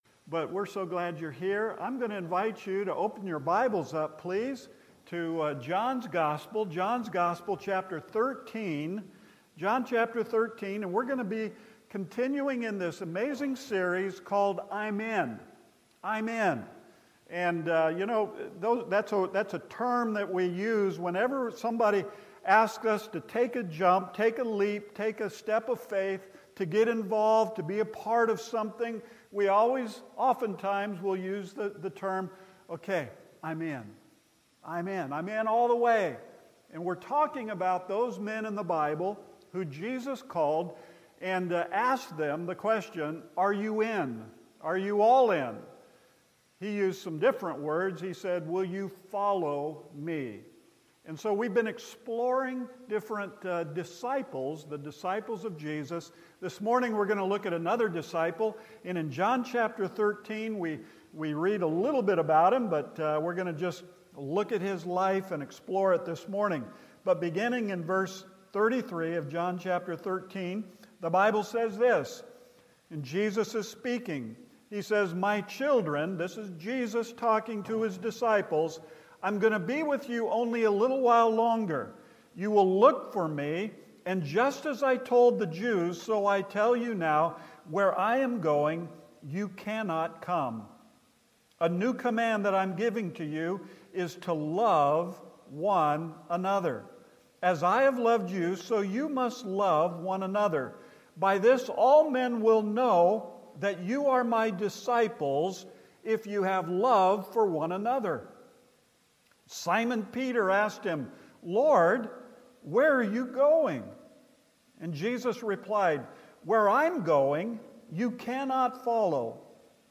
Central Baptist Church Sermons